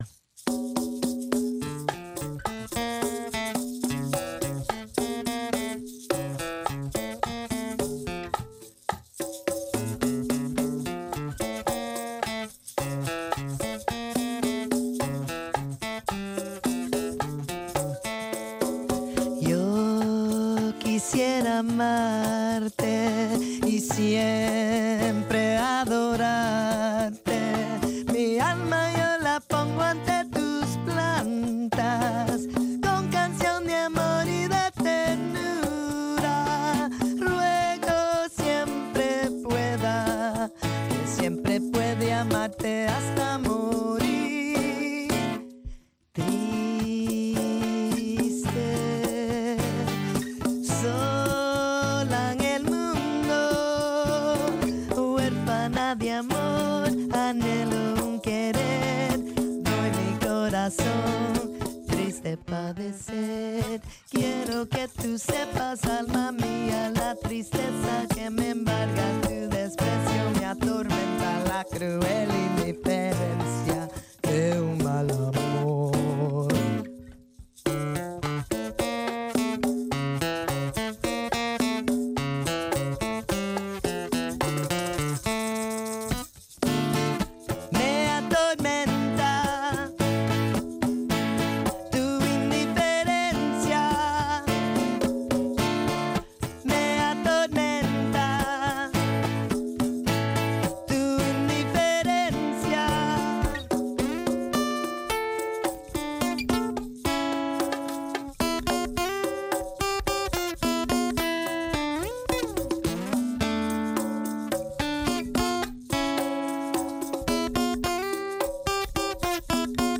le percussionniste
ont joué pour la première fois en studio